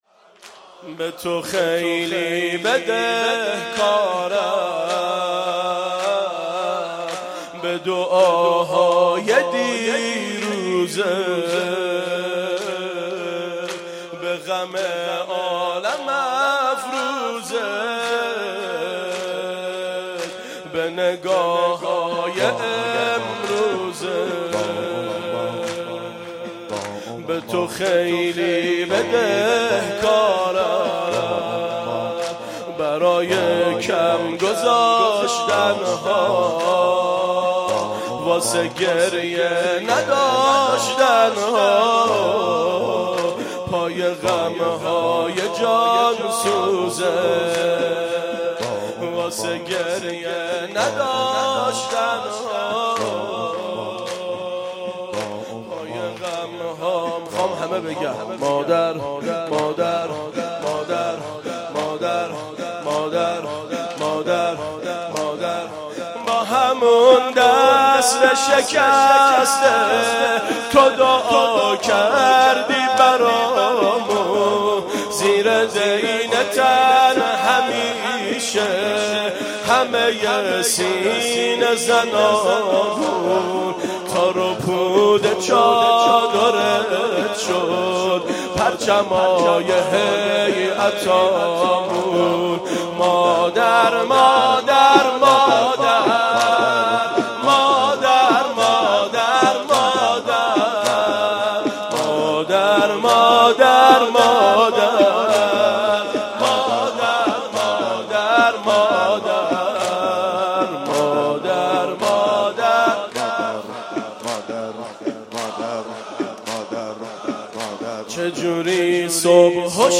فاطمیه 1396